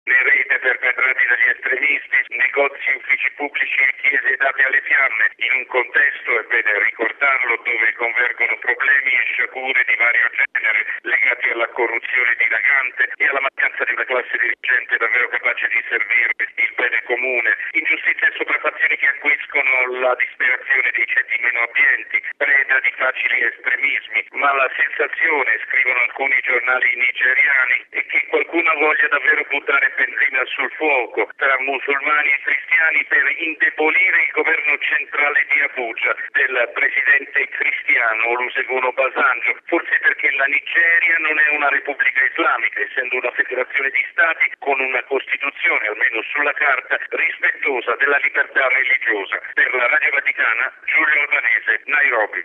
Da Nairobi il servizio